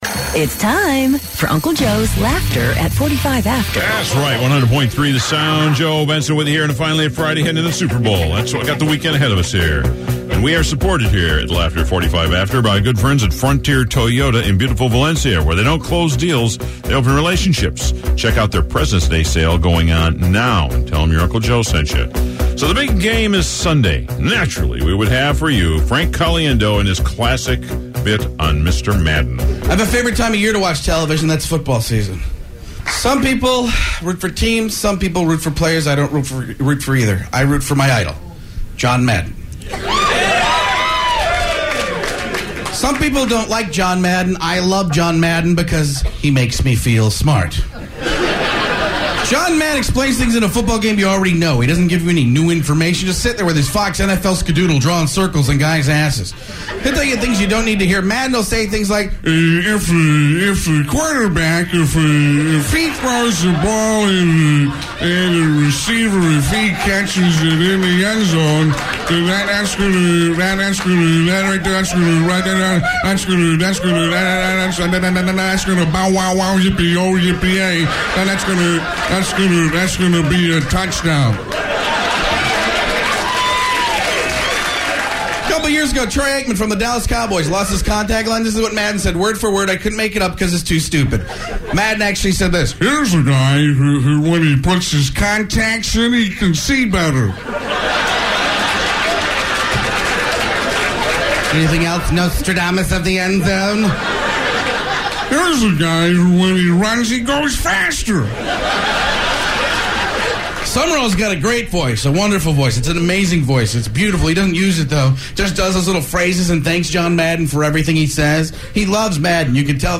Frank Caliendo's classic John Madden impression.